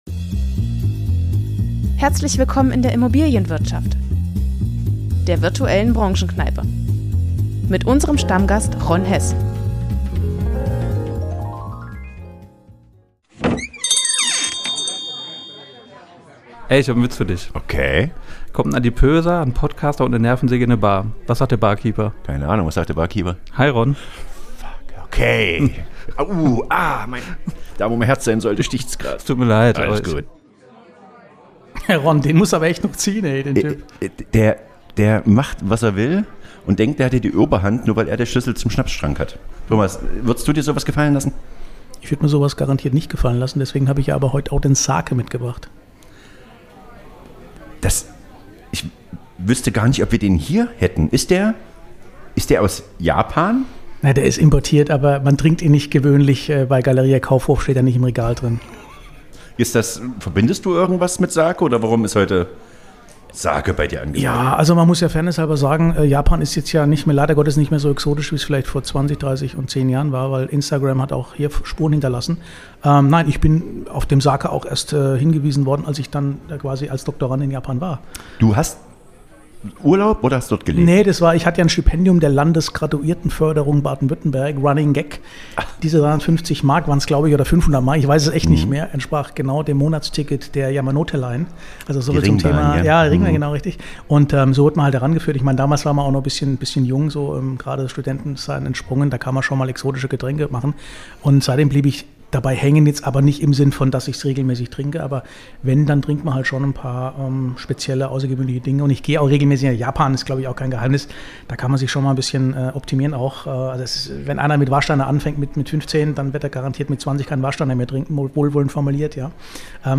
Die Soundeffekte kommen von Pixabay.